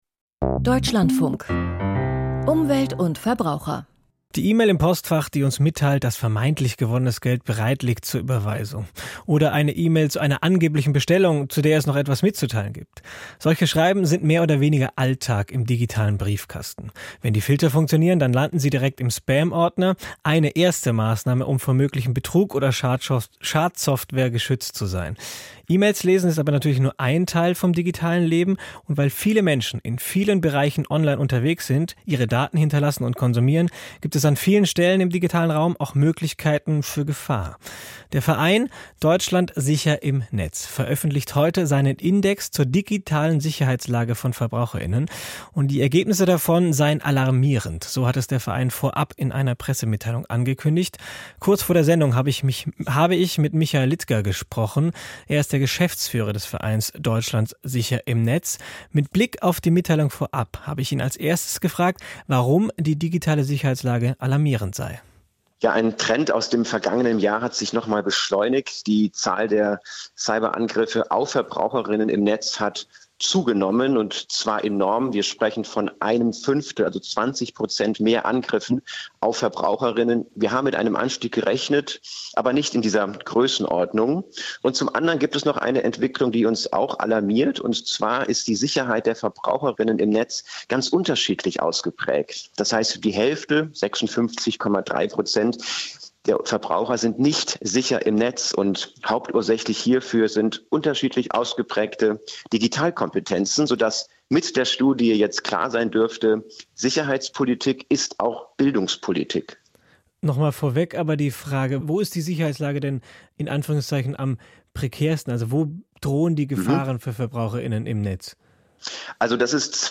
DsiN Sicherheitsindex - Interview